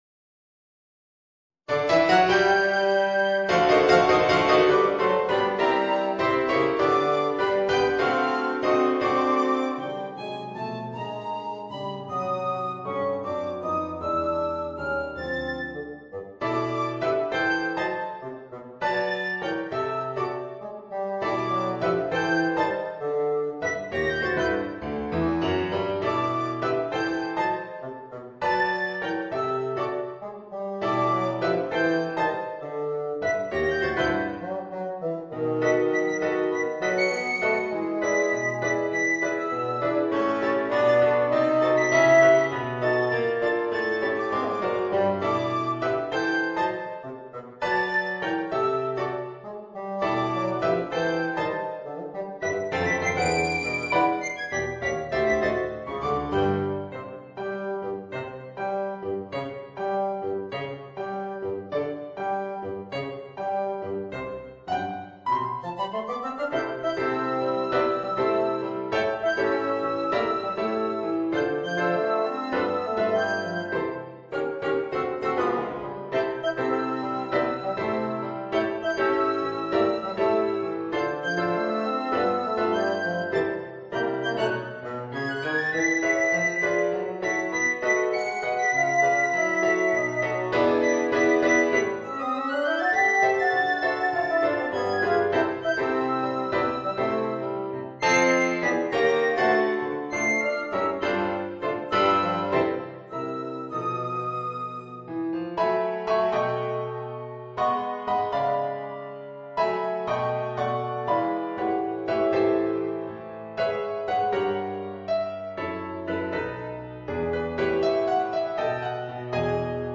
Voicing: Piccolo, Bassoon and Piano